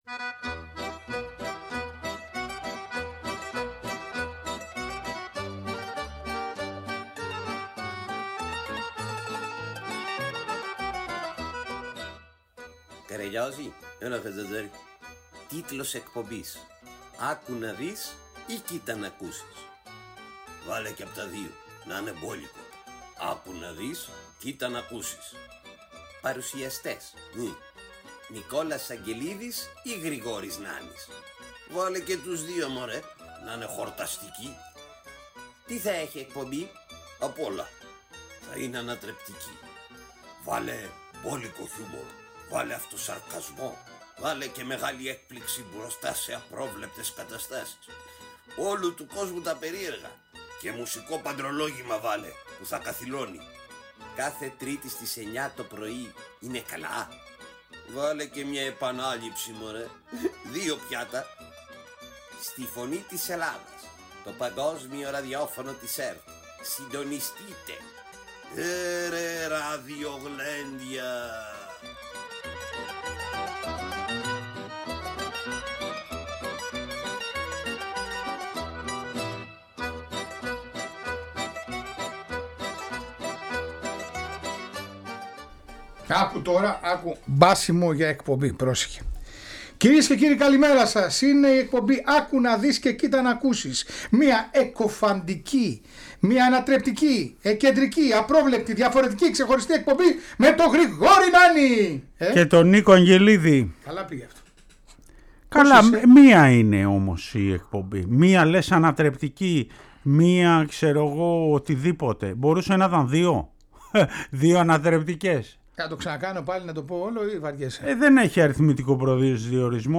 Τέλος μαθαίνουμε τη μεγάλη ιστορικού του θρυλικού Α.Ο. Χαλκίδας και ακούμε τον ύμνο του